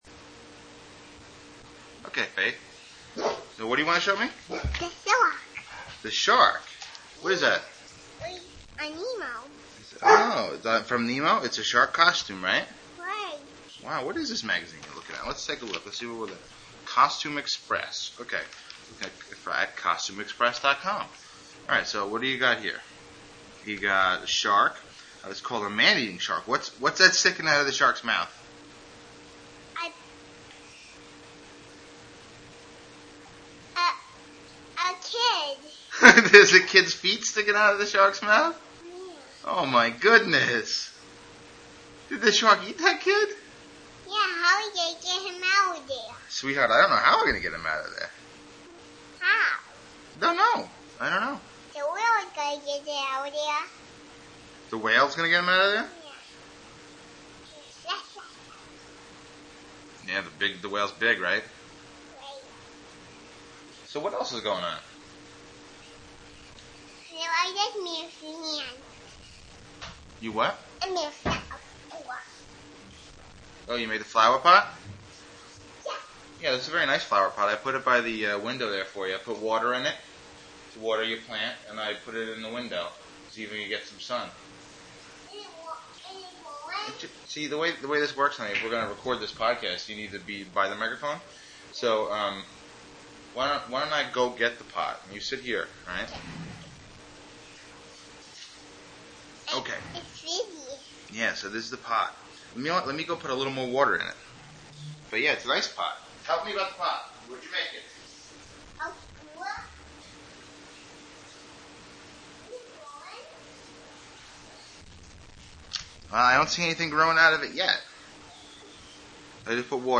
We talked about a catalog she found laying around, her flower pot, and the movie Ratatouille. I can't guarantee any regularity or quality here and you will notice that there is very little production work done here--no music, sound effects, etc. I used a bad microphone and had ambient noise.